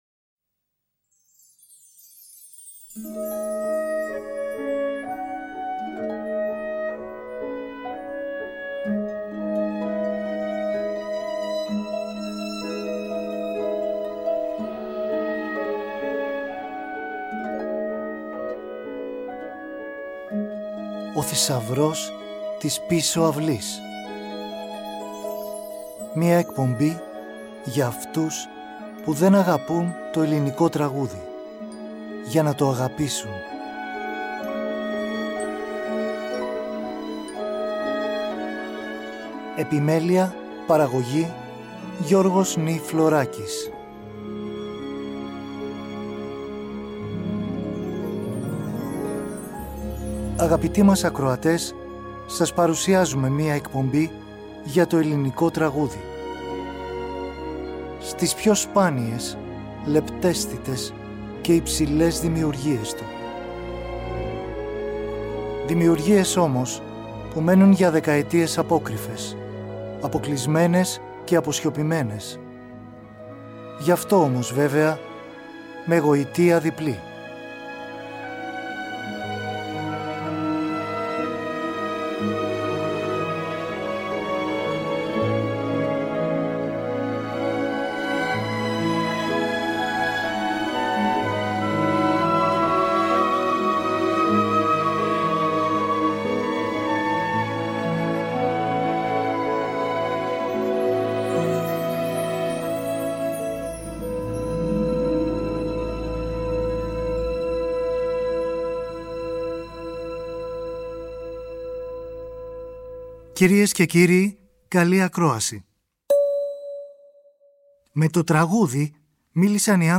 Ελληνικη Μουσικη στο Τριτο